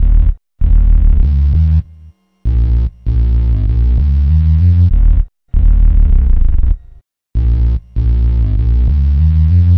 Hands Up - Sub Bass.wav